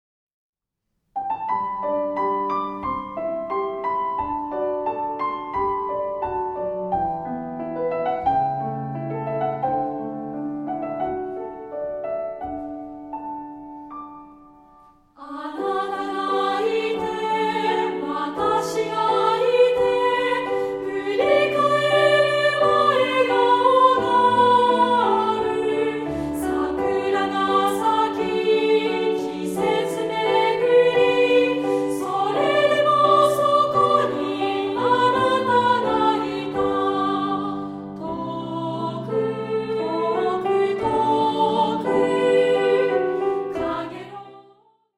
2部合唱／伴奏：ピアノ